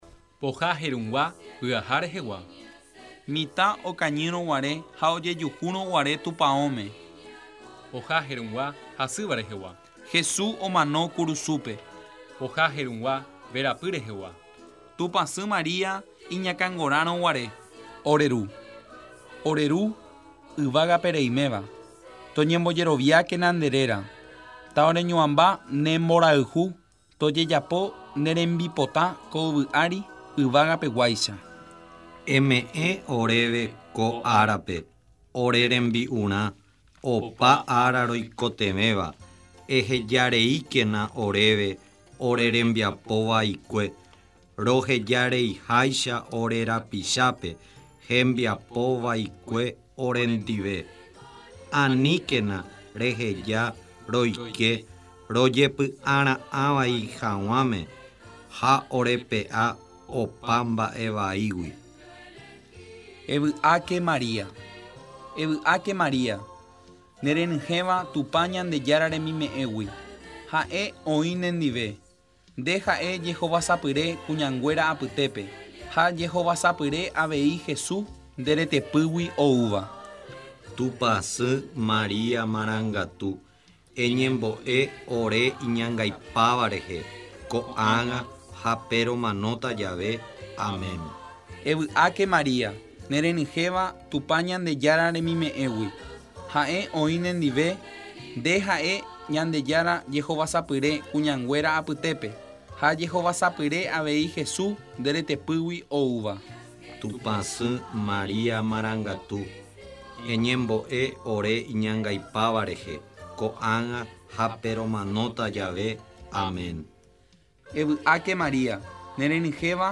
El arpa que se escucha como música de fondo
en los estudios de Radio Canal 100